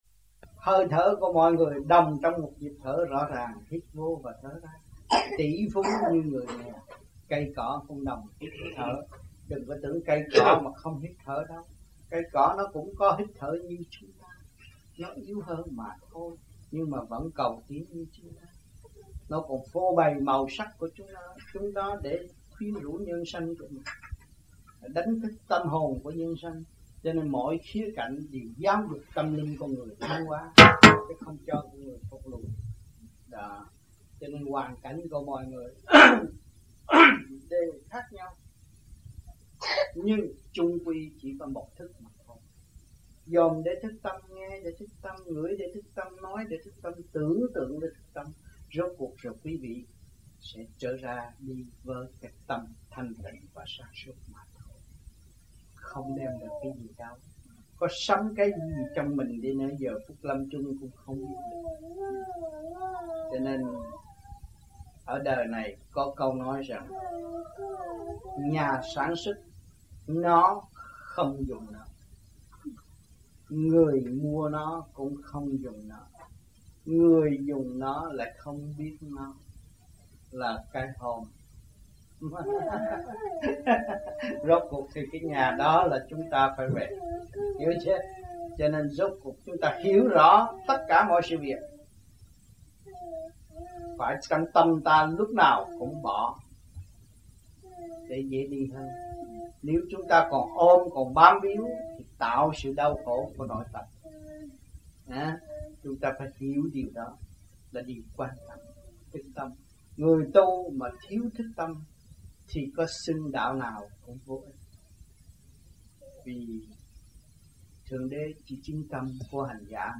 1982 Đàm Đạo
1982-12-09 - AMPHION - LUẬN ĐÀM 1